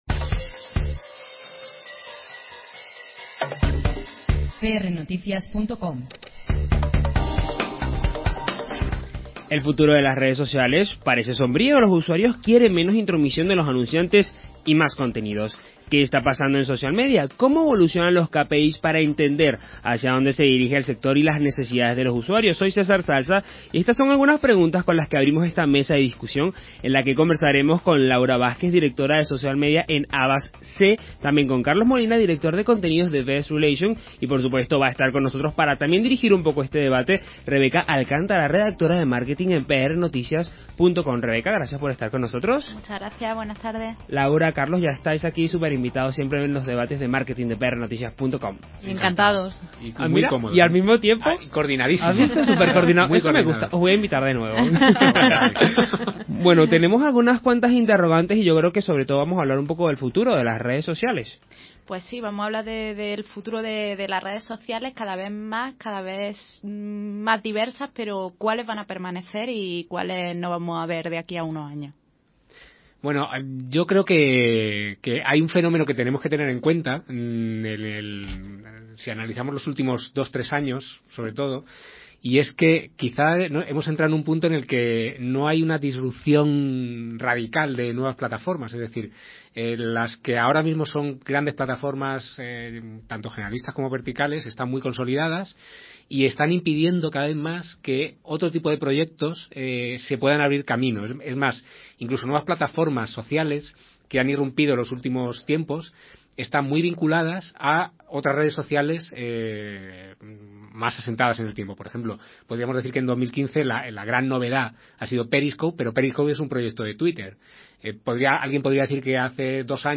Estas y otras cuestiones son las que hemos tratado de responder en Onda CRO durante el debate de Marketing de esta semana